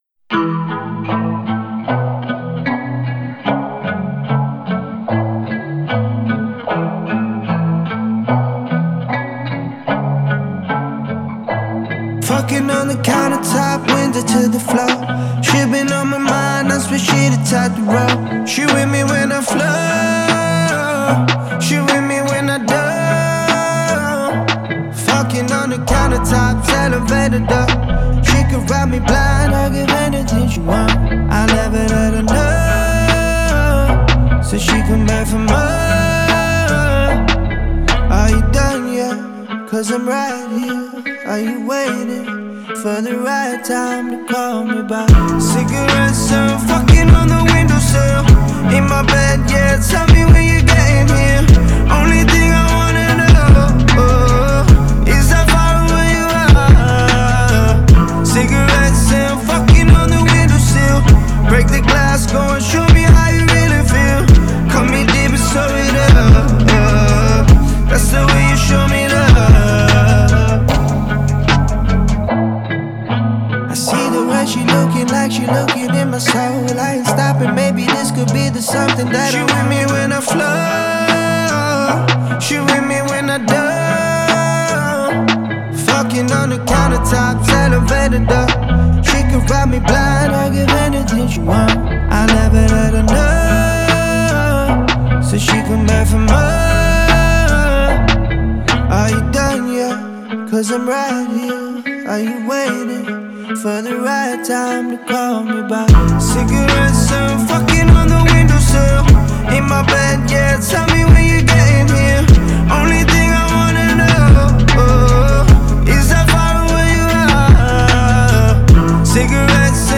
элементы R&B и хип-хопа
Звучание отличается глубокими басами и плавными мелодиями